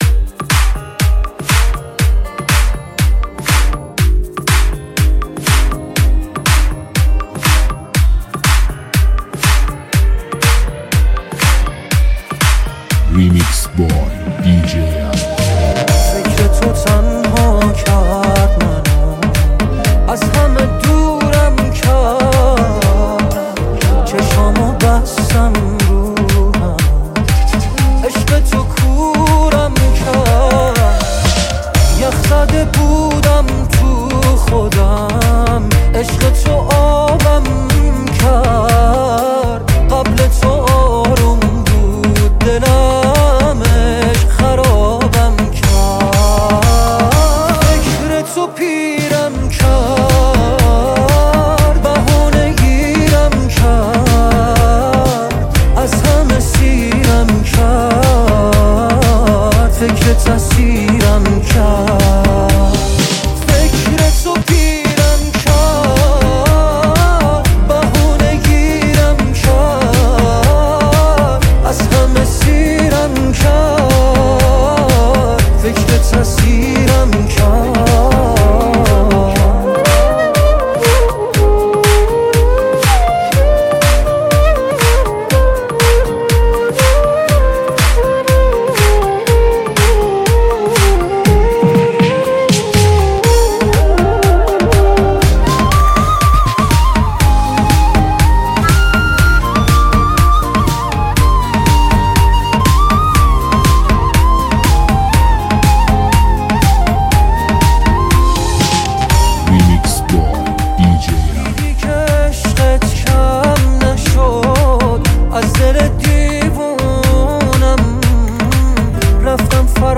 آهنگ ریمیکس